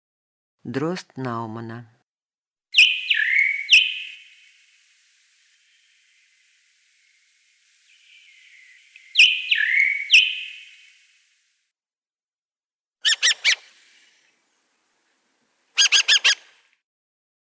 Рыжий дрозд Науманна